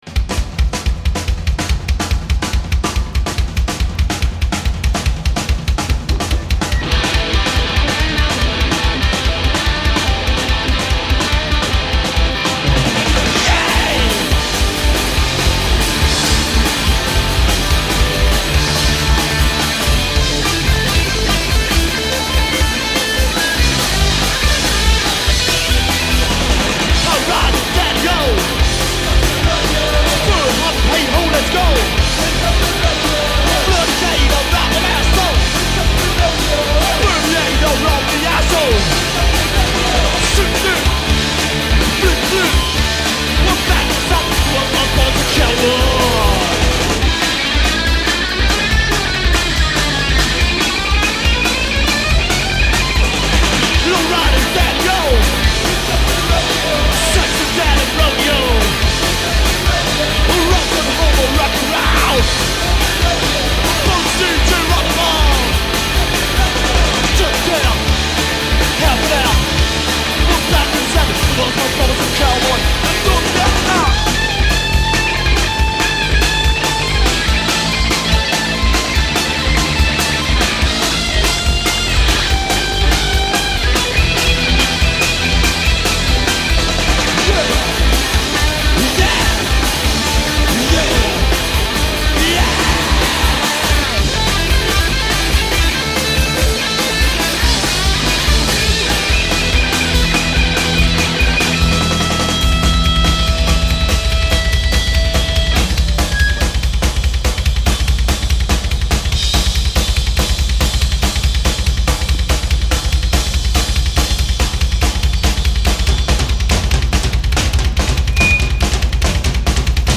Live at the Zapata - Berlin, Tacheles 18.6.2005